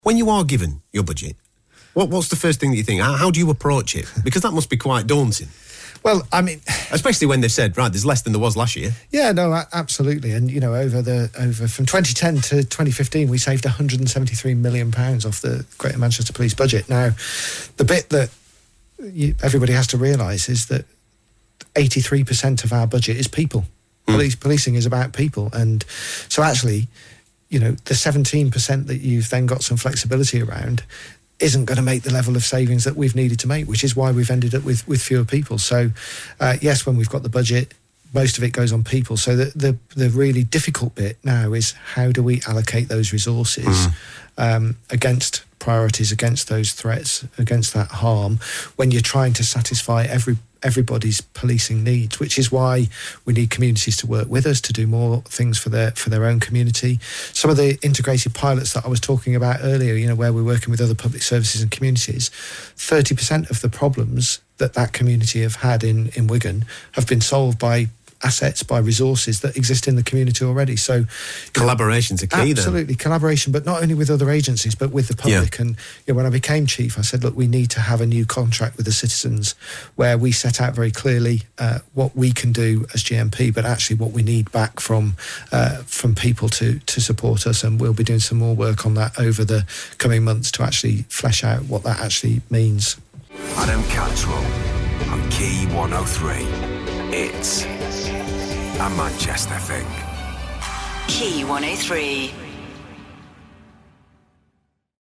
late night talk & phone-in show on Key 103